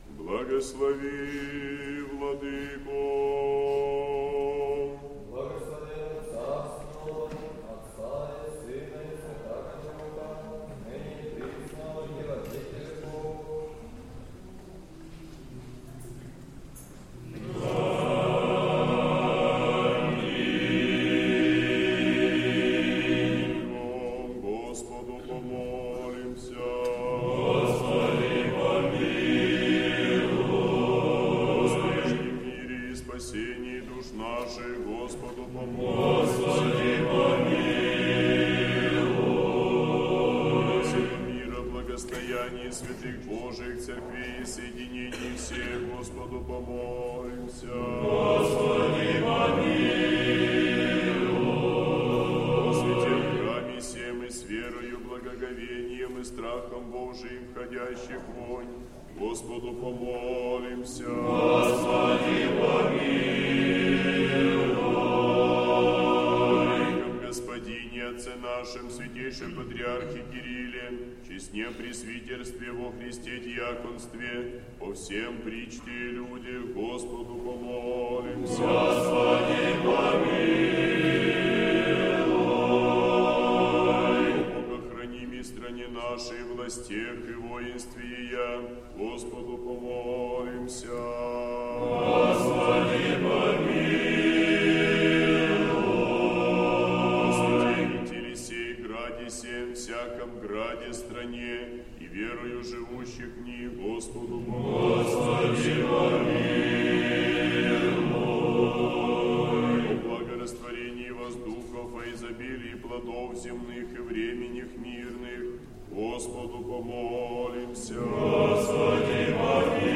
Божественная литургия в Сретенском монастыре в день Покрова Пресвятой Богородицы
Хор Сретенской Духовной семинарии.